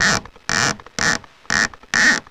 Index of /90_sSampleCDs/E-MU Producer Series Vol. 3 – Hollywood Sound Effects/Miscellaneous/WoodscrewSqueaks
WOOD SQUEA03.wav